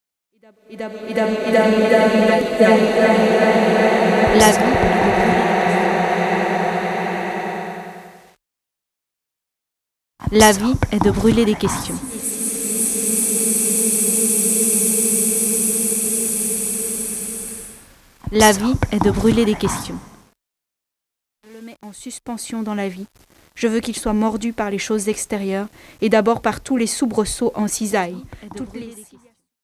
Sound Art Series